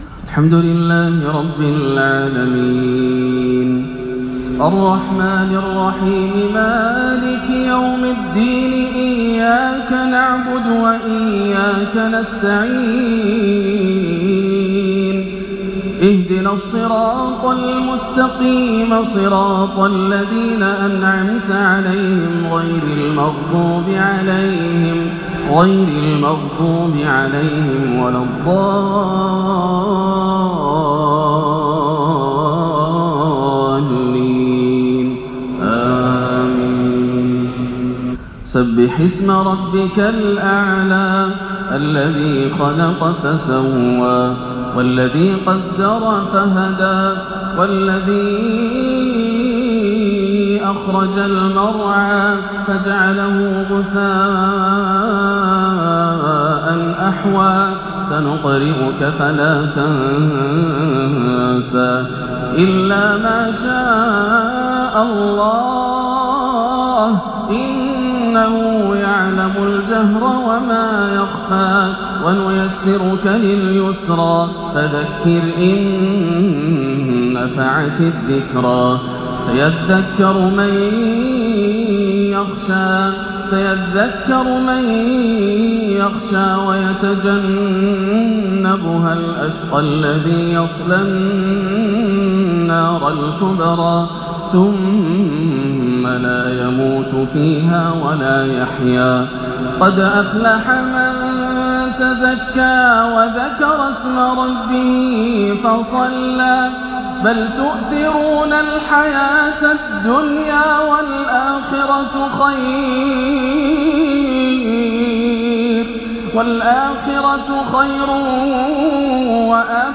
سورتي الأعلى والغاشية بأداء وتفاعل عجيب مؤثر | صلاة الجمعة 14-6 > عام 1439 > الفروض - تلاوات ياسر الدوسري